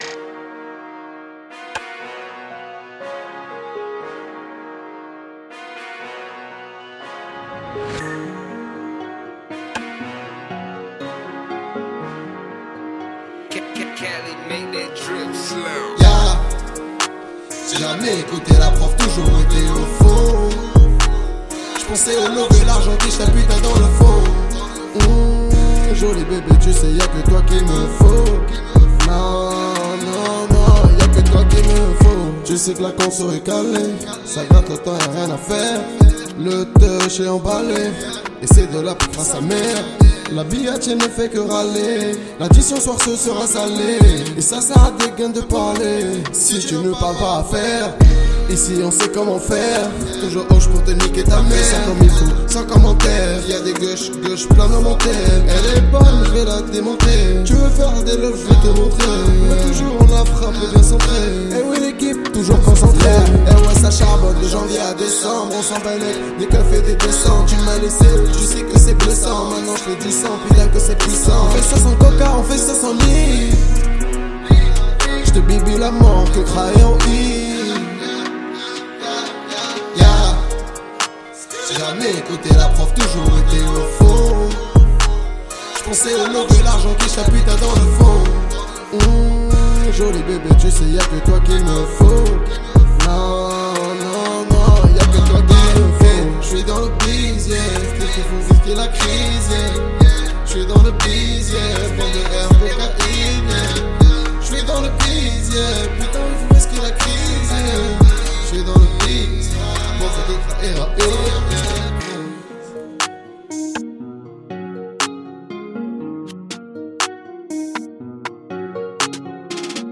Bandes-son
Music et chant et lyrics by me (maquette)